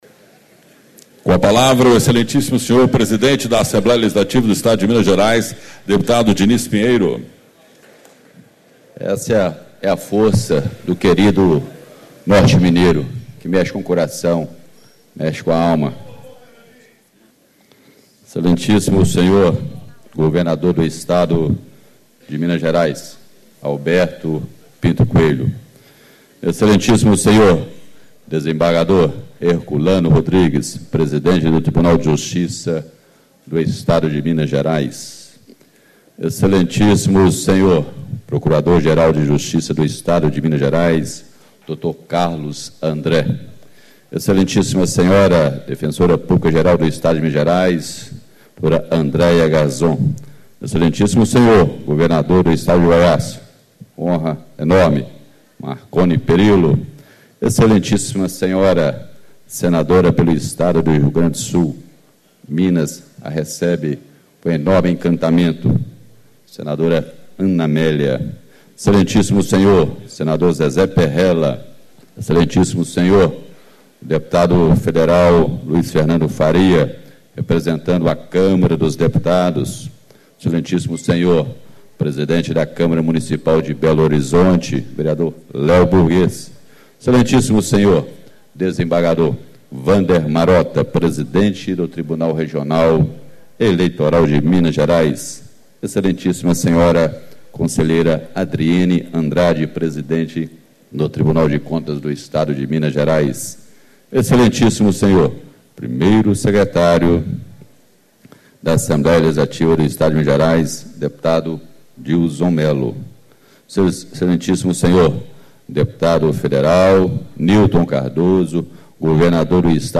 Discurso - Deputado Dinis Pinheiro, PP, Presidente da Assembleia Legislativa do Estado de Minas Gerais
Reunião Solene destinada à posse do Governador do Estado